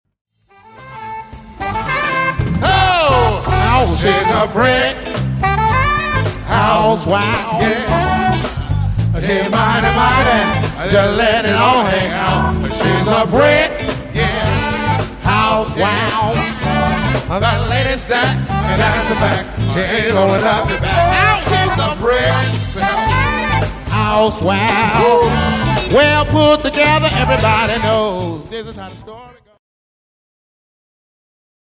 Popular, Funk, Dance